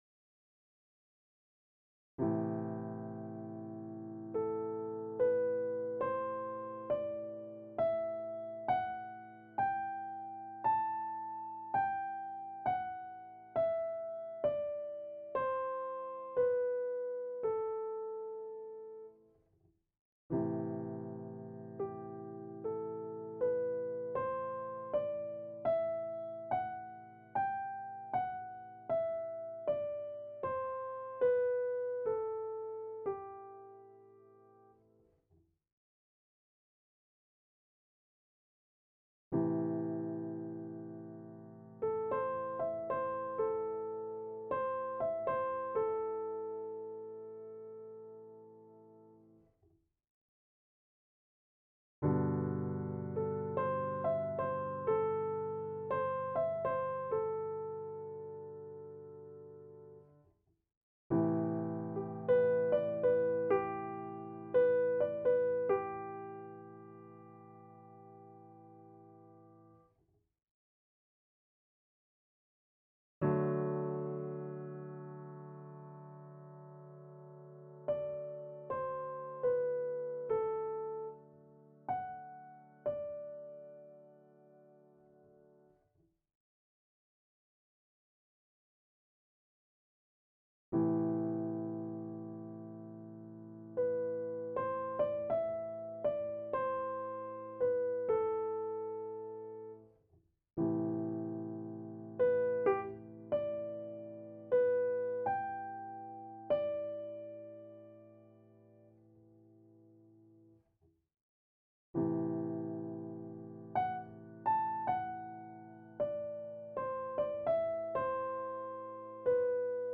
Wenn man die Tonleiter spielt, hört man, dass es eine völlig andere Atmosphäre erzeugt, je nachdem ob man von A oder von G aus spielt.
Aber da ich nicht improvisieren kann, konnte ich das jetzt nur mit einzelnen Tönen ausprobieren. Anhänge a-moll dorisch.mp3 a-moll dorisch.mp3 5 MB